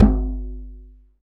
Index of /90_sSampleCDs/NorthStar - Global Instruments VOL-2/PRC_Djembe/PRC_Djembe